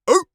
seal_walrus_bark_single_03.wav